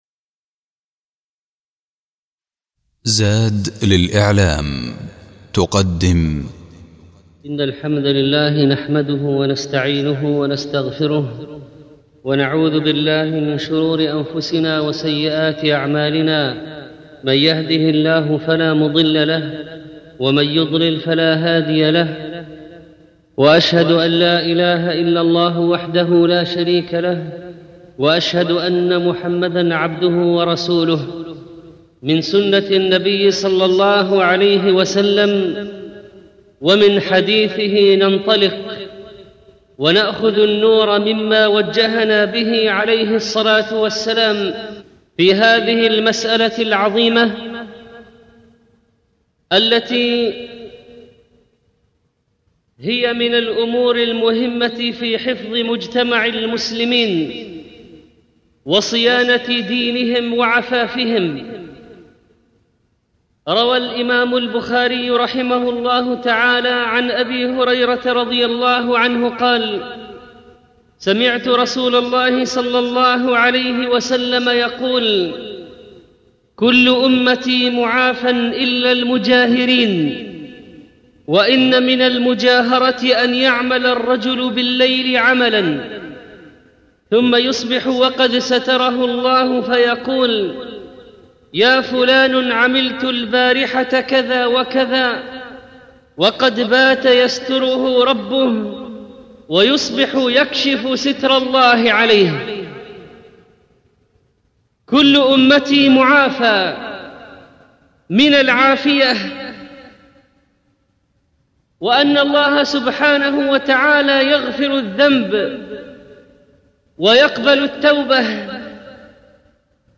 الخطبة الأولى معنى المجاهرة بالمعاصي